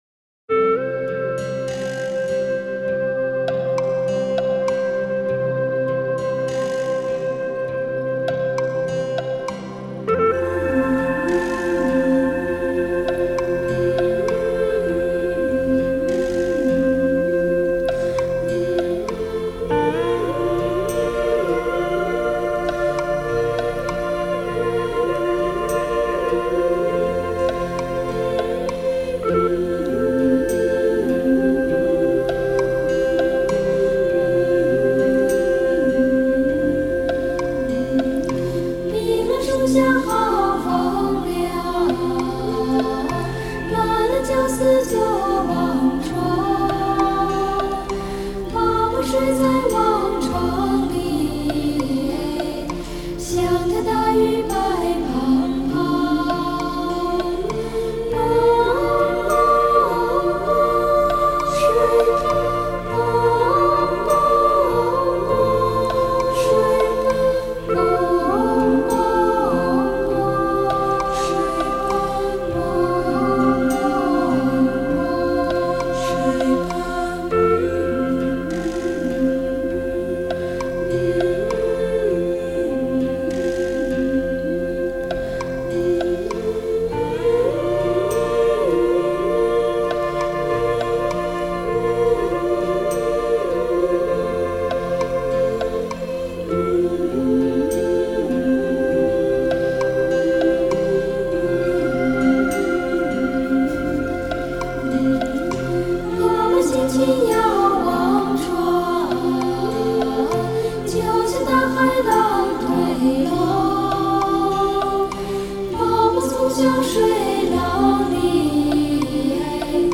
民族音乐
纯音乐